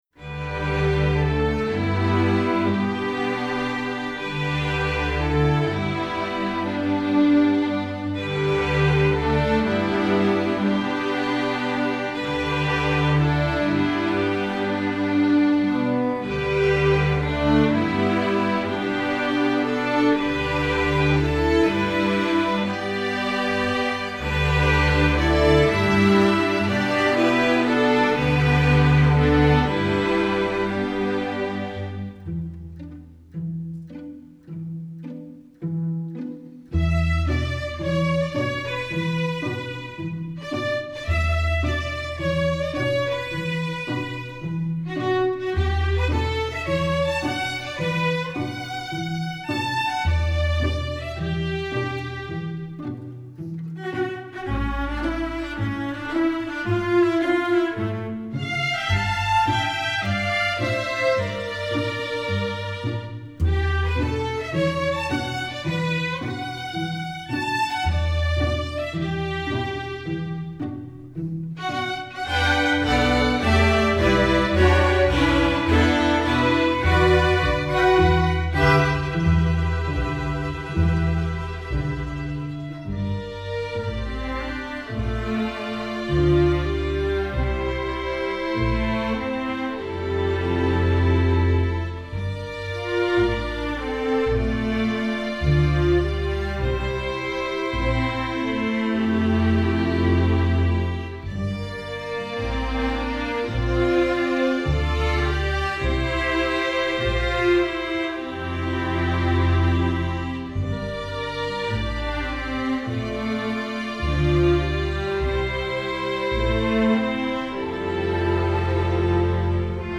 video game